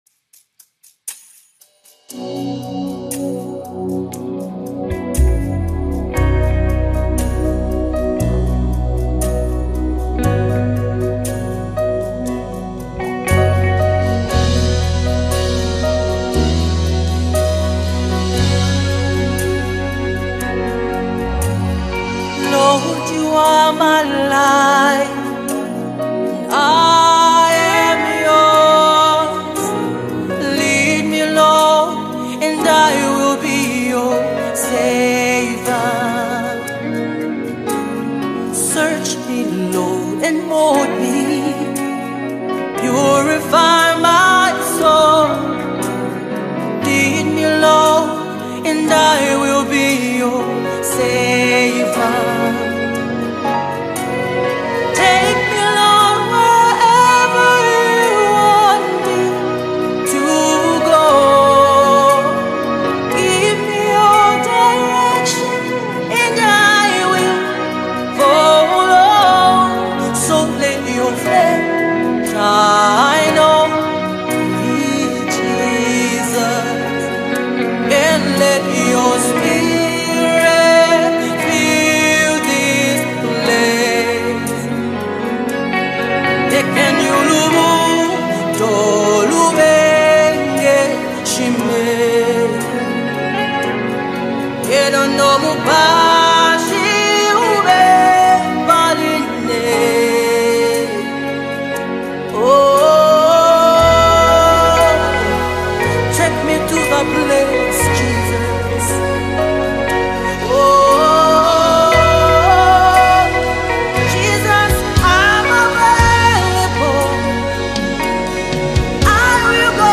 Multiple award-winning Zambian gospel singer